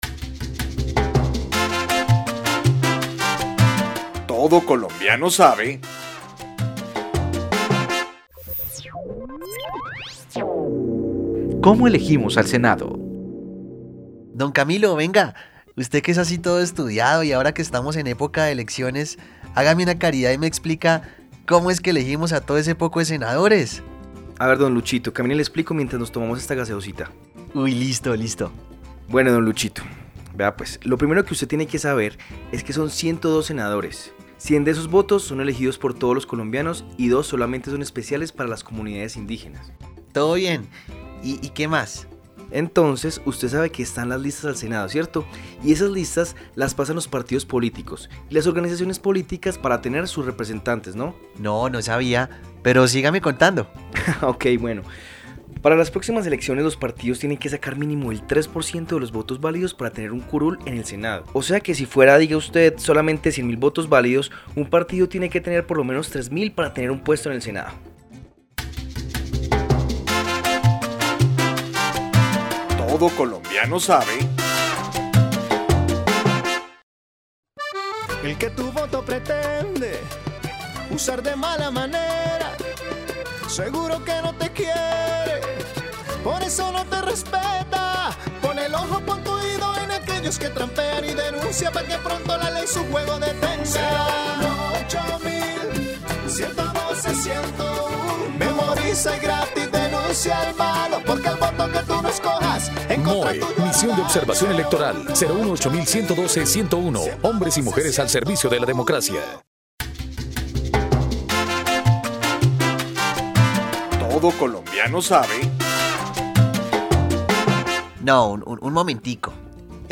Programas de radio , Participación ciudadana en Colombia , Elecciones y democracia en Colombia , Control político y rendición de cuentas , Irregularidades y delitos electorales , Colombia -- Grabaciones sonoras